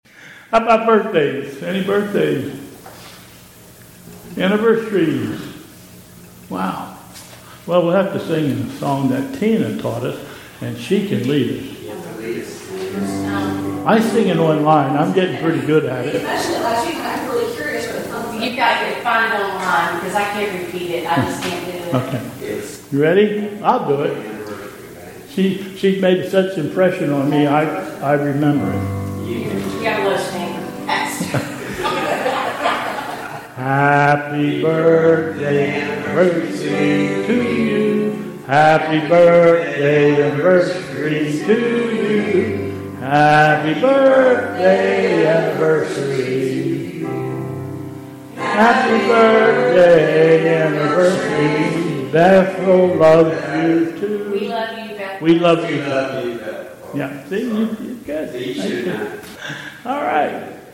Bethel Church Service
Any other announcements?...